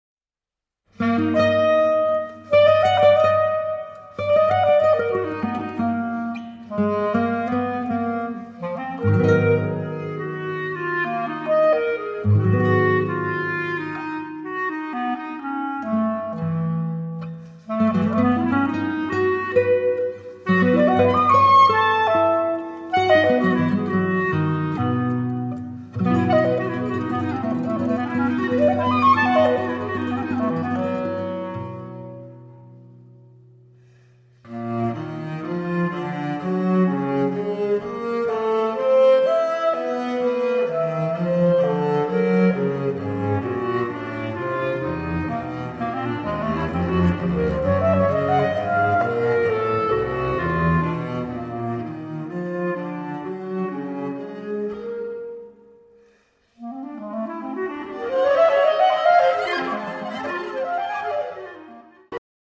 sax contralto/clarinetto
violoncello
chitarre
contrabbasso
batteria